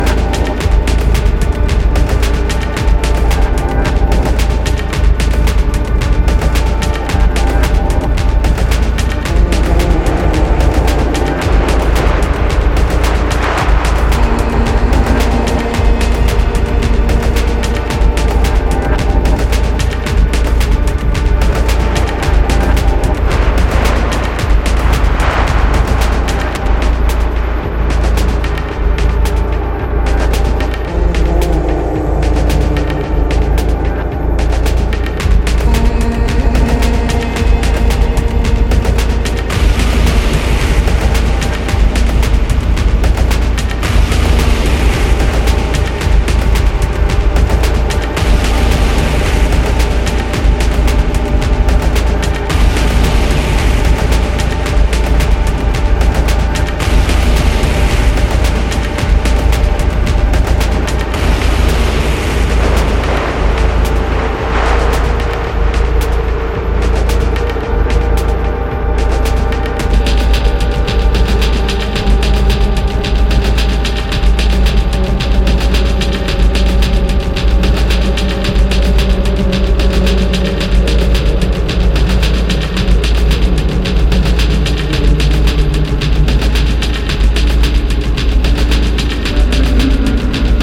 IDM/Electronica